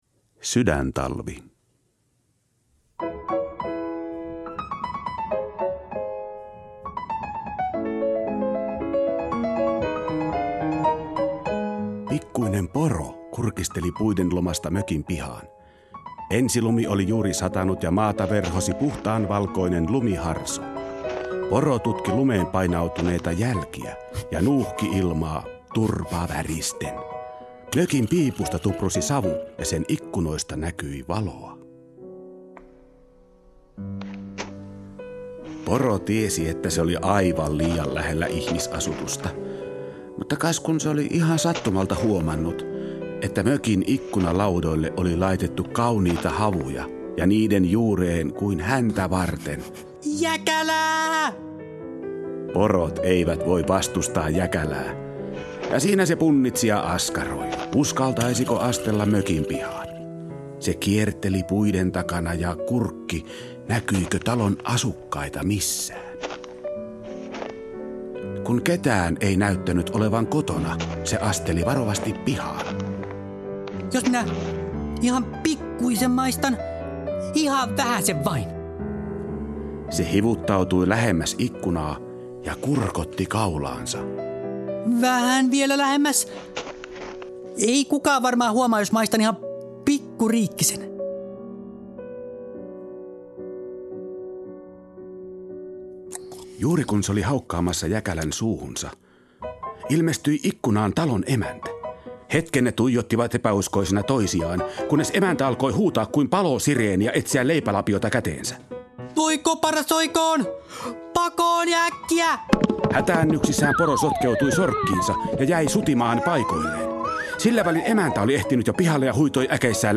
Maaria Taivaanlaulaja on ääninäyttelijöiden esittämä musiikkisatu, jonka juuret ovat Lapin monimuotoisessa luonnossa.